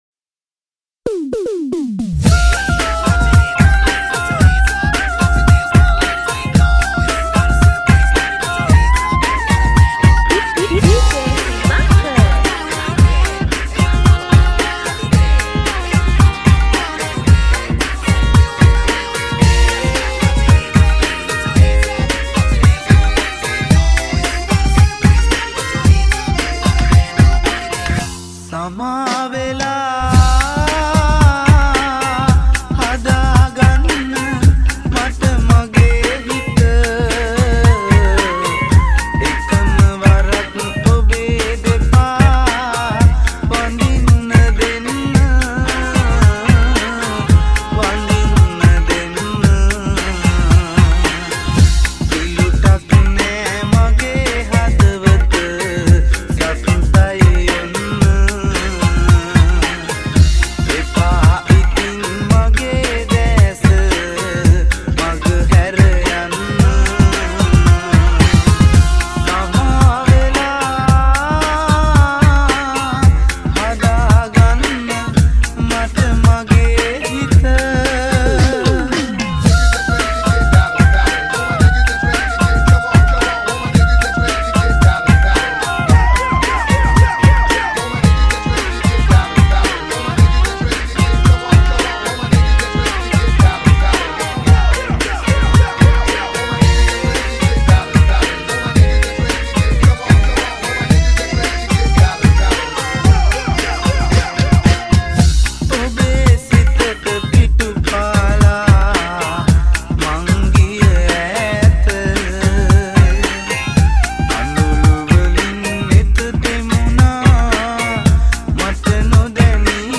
High quality Sri Lankan remix MP3 (19).